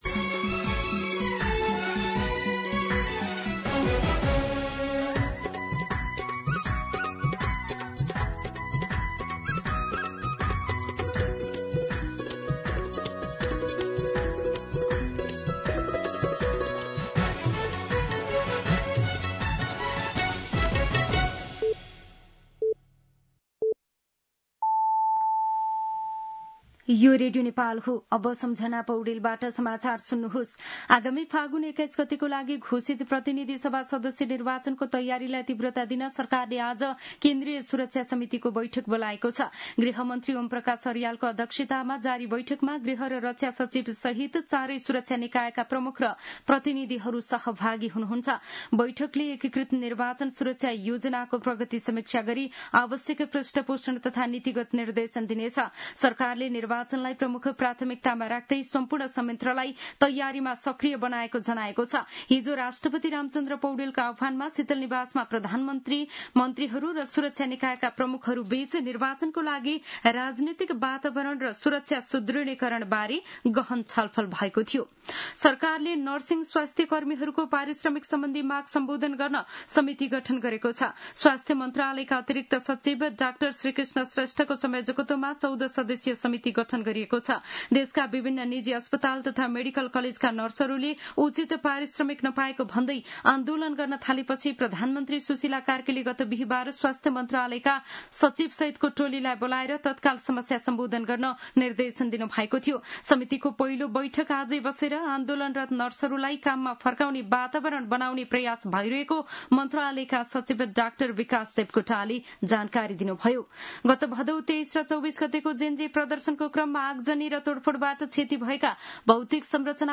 दिउँसो १ बजेको नेपाली समाचार : १८ पुष , २०२६
1pm-Nepali-News.mp3